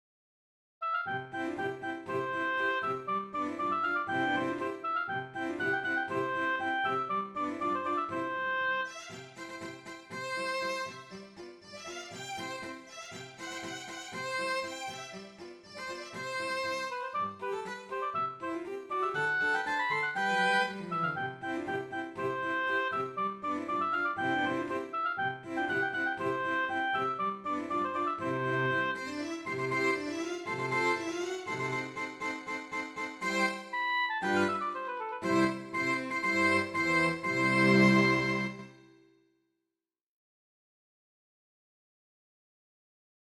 The first movement of a Quartettino for oboe and strings, attributed to Swabia’s
by the renowned MacFinale Ensemble playing period midi instruments!
Rondo